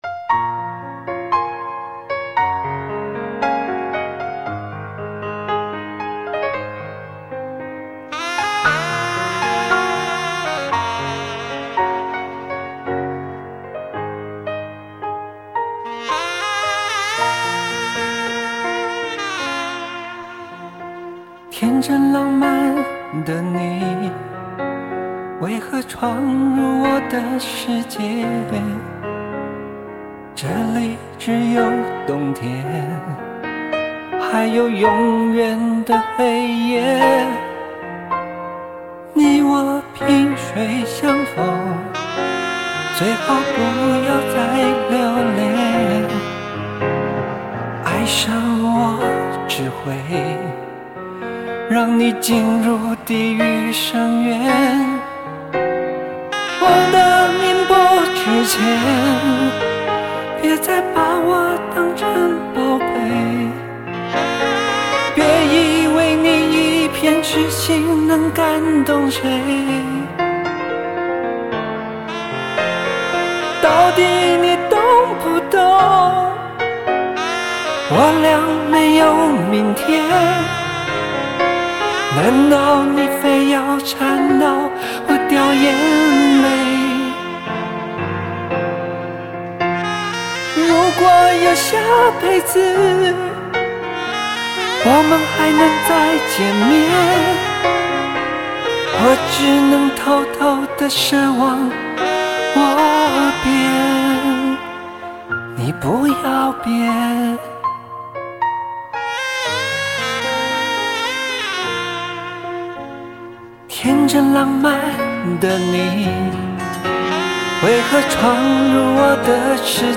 有着强烈的叙事试词曲结构，后者更神似电影情节的情境插曲
似乎就连他略失音准的演唱也成了风格化的一角